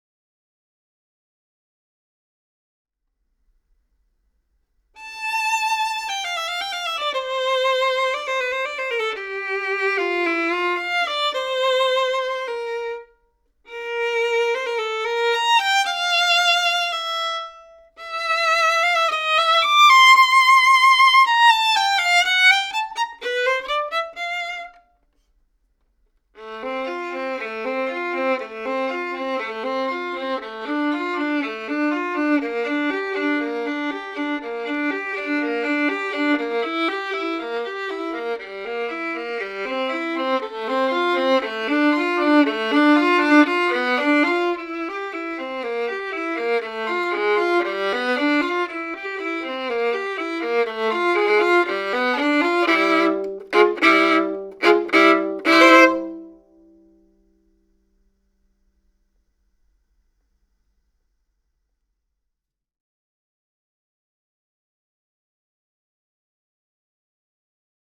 リアルガチ版は、フィルター「sonezaki」（変換目安100時間）。